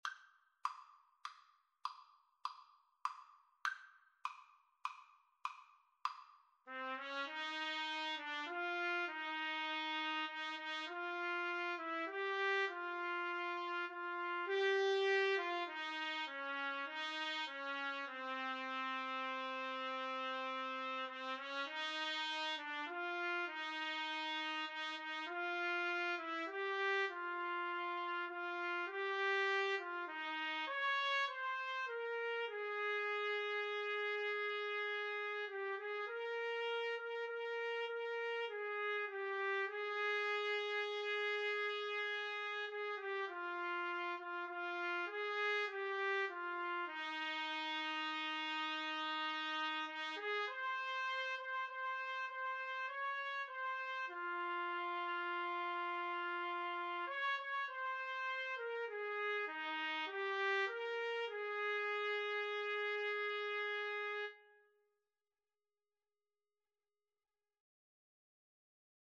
6/8 (View more 6/8 Music)
Classical (View more Classical Trumpet Duet Music)